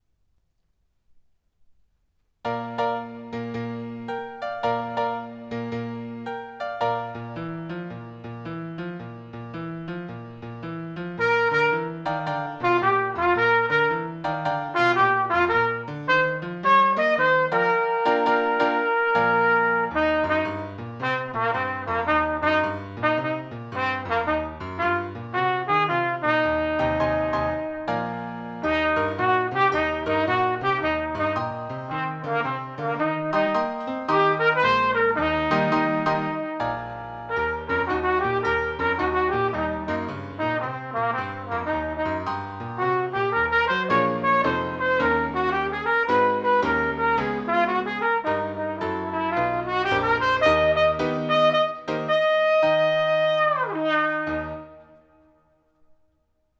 Short jazzy piece